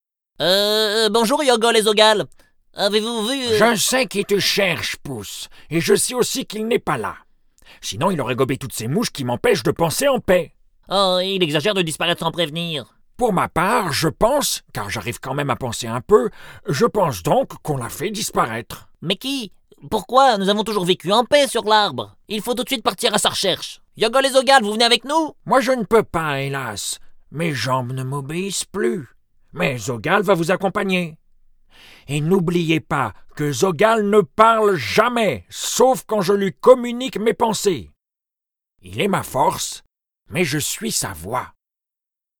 Dessin-animé/personnages
Yogol et Pouce: voix aiguë dynamique/jeune homme et voix grave/vieux sage